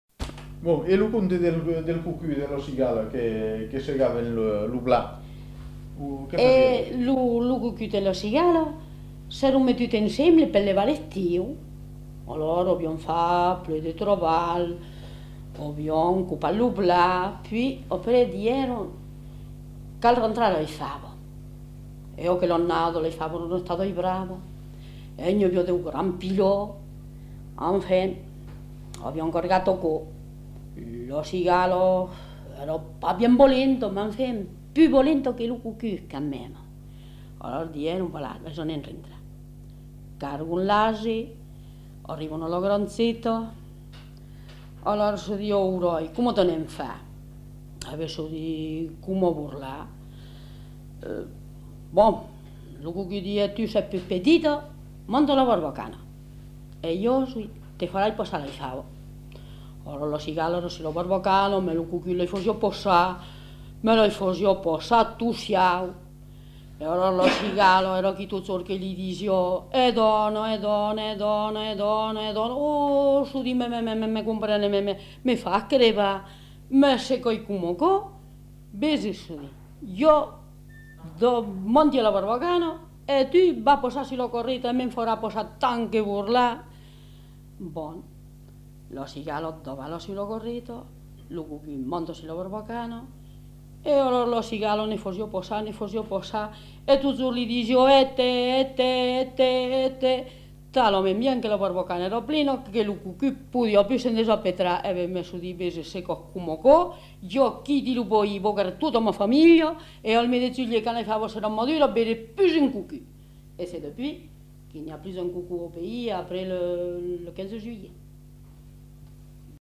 Aire culturelle : Périgord
Lieu : Castels
Genre : conte-légende-récit
Effectif : 1
Type de voix : voix de femme
Production du son : parlé